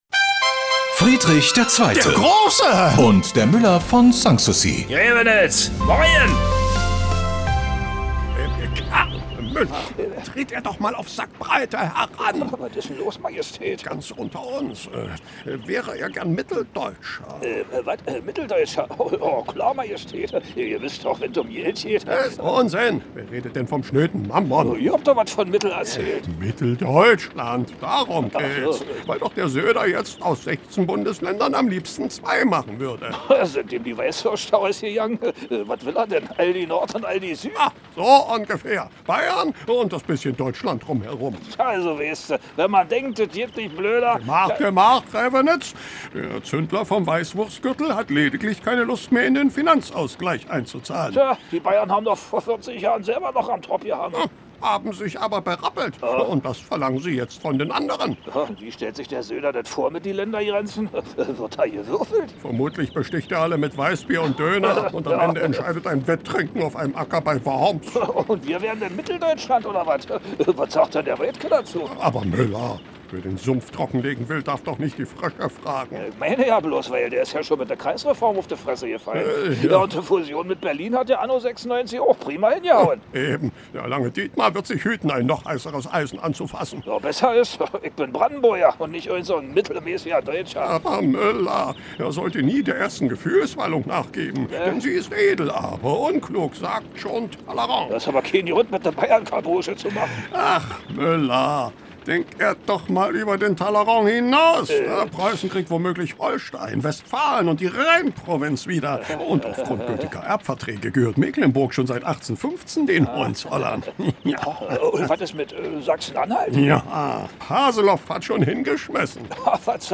Der legendäre Nachbarschaftsstreit setzt sich bis heute fort: Preußenkönig Friedrich II. gegen den Müller von Sanssouci. Immer samstags kriegen sich die beiden bei Antenne Brandenburg in die Haare.
Regionales , Comedy , Radio